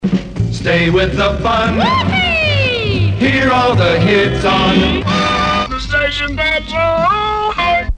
Jingles (known as 'station id's)